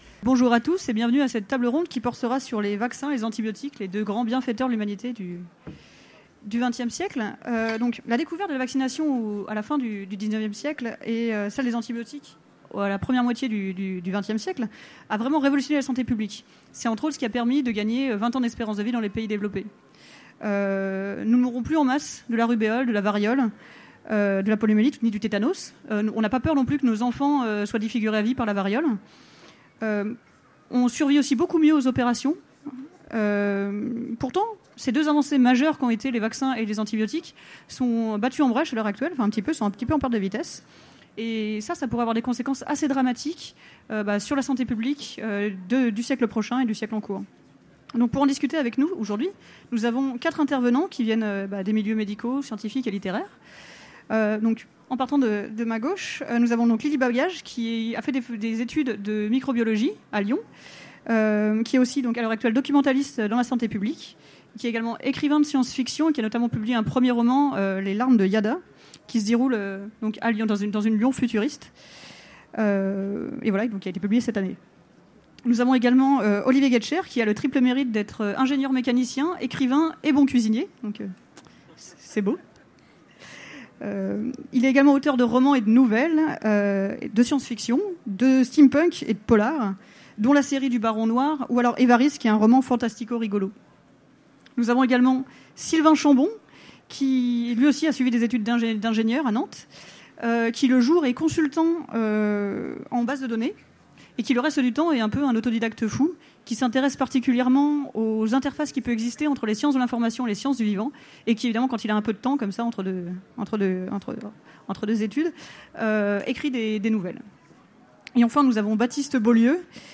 Utopiales 2017 : Conférence Vaccins et antibiotiques, histoire et aléas de deux sauveurs de l’Humanité